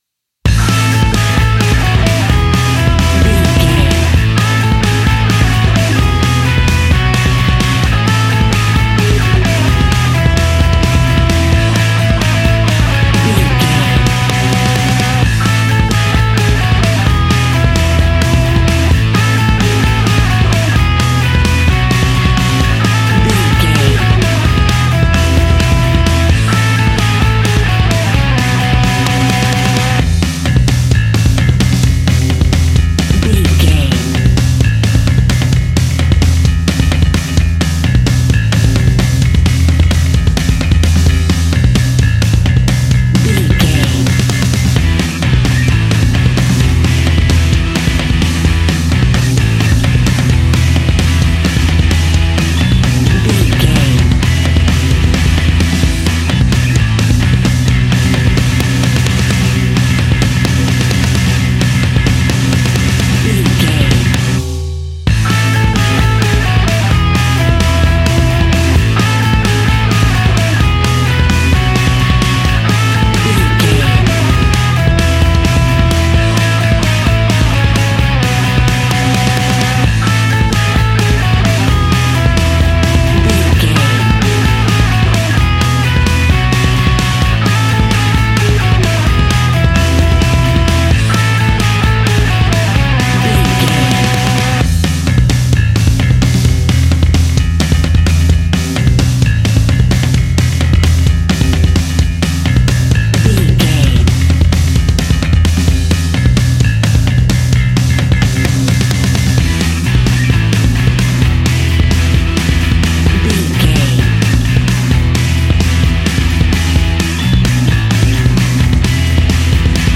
Ionian/Major
energetic
driving
aggressive
electric guitar
bass guitar
drums
hard rock
punk metal
instrumentals
distorted guitars
hammond organ